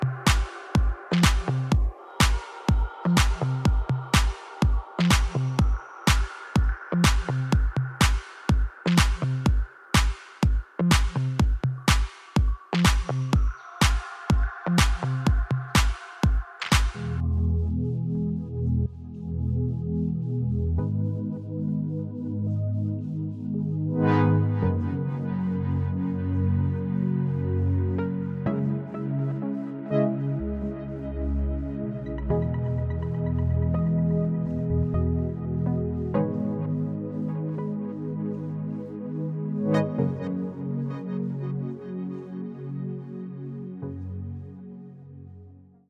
Temes musicals